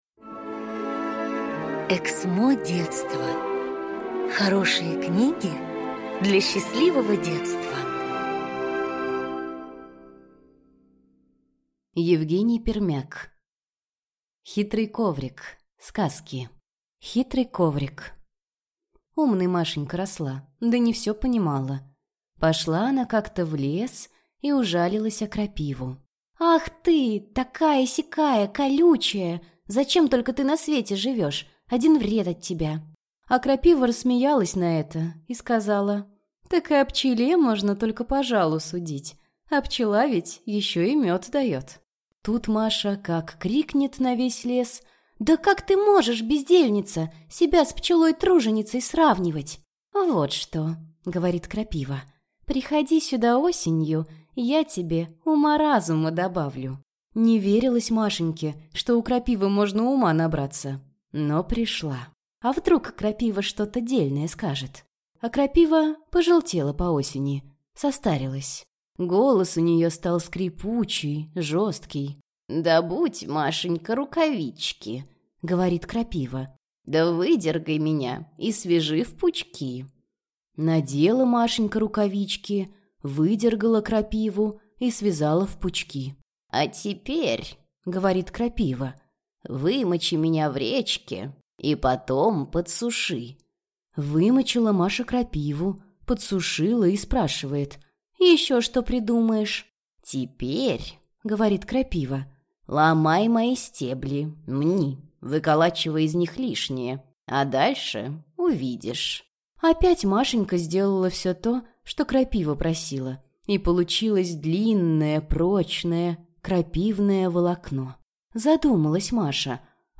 Аудиокнига Хитрый коврик | Библиотека аудиокниг